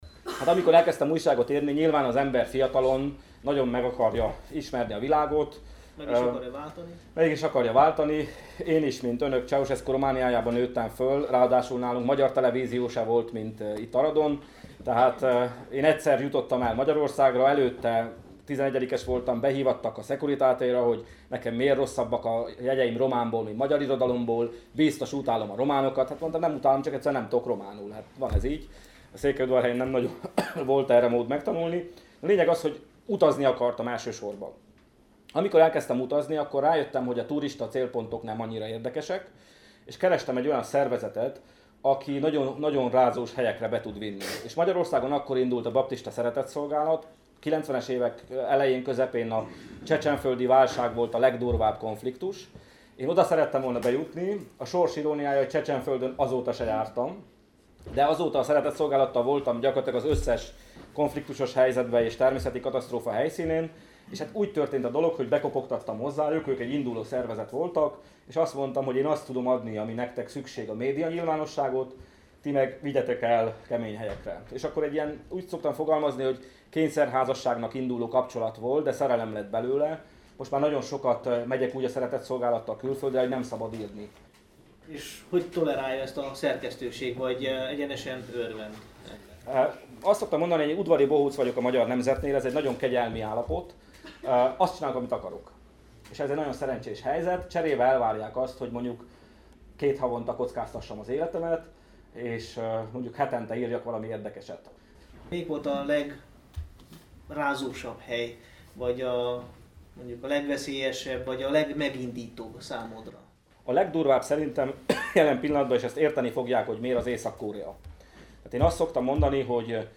Alább meghallgatható egy részlet az előadásból, amelyben a megívott pályája kezdetéről, a Baptista Szeretetszolgálattal kialakult kapcsolatáról és Észak-Koreáról mesél.